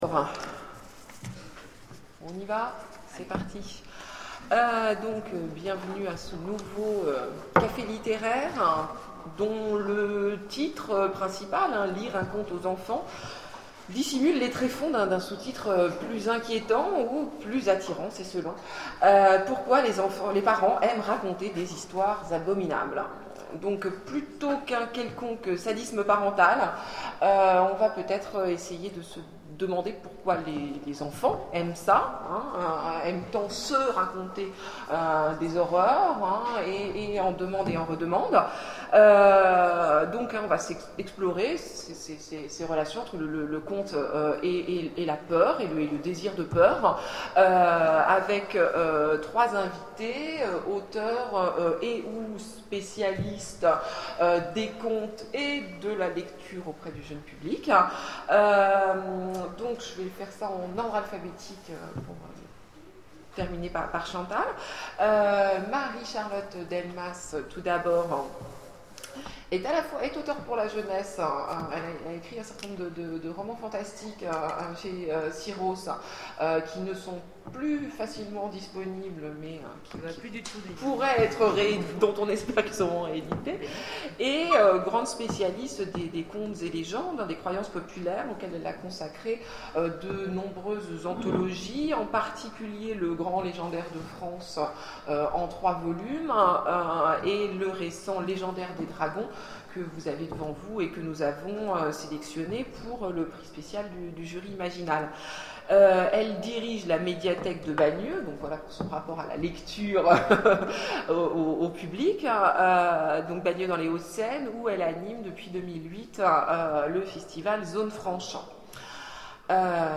Imaginales 2011 : Lire un conte aux enfants...
Mots-clés Contes Conférence Partager cet article